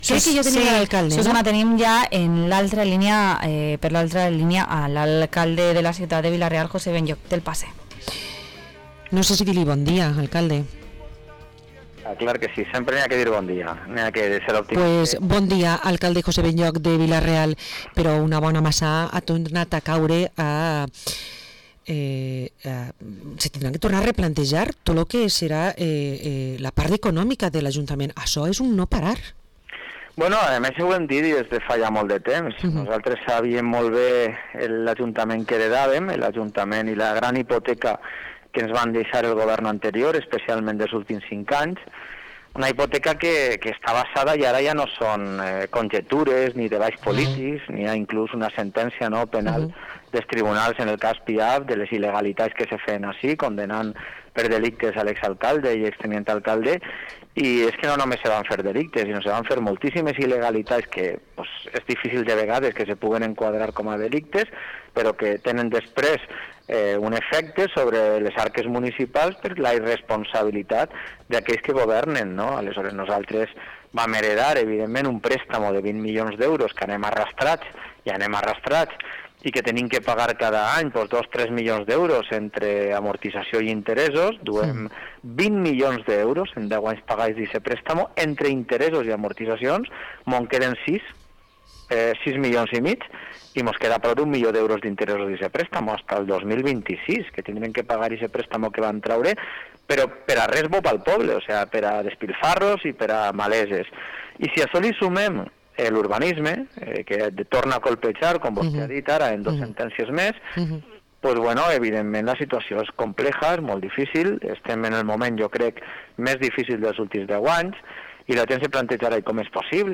Entrevista al alcalde de Vila-real, José Benlloch